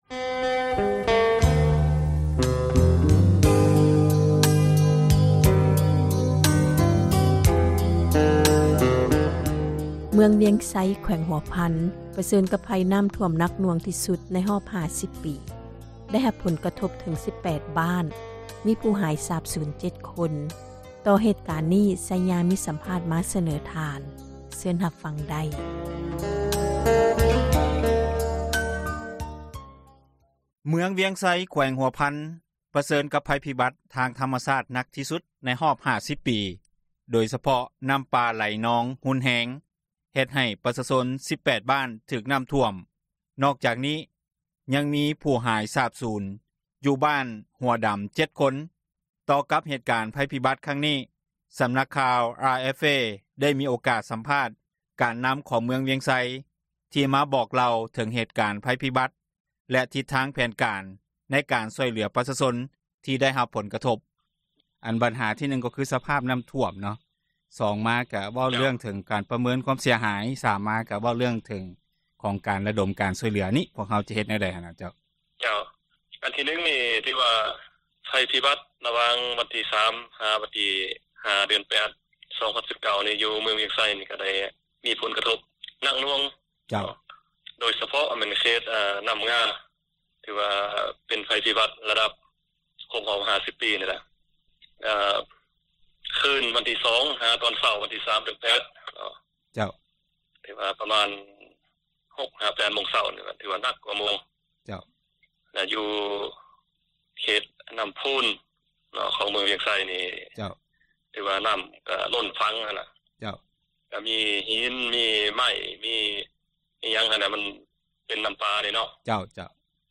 ປັດຈຸບັນທາງເມືອງວຽງໄຊ ປະເມີນຄວາມເສັຽຫາຍ ເບື້ອງຕົ້ນປະມານ 13 ຕື້ປາຍກີບ. ຕໍ່ກັບເຫດການພັຍພິບັດ ຄັ້ງນີ້ ສຳນັກຂ່າວ RFA ໄດ້ມີໂອກາດສັມພາດ ການນໍາຂອງເມືອງວຽງໄຊ ທີ່ມາບອກເລົ່າເຖິງເຫດການພັຍພິບັດ ແລະ ທິດທາງແຜນການ ໃນການຊ່ອຍເຫຼືອ ປະຊາຊົນທີ່ໄດ້ຮັບຜົລກະທົບ. ສຽງສັມພາດ.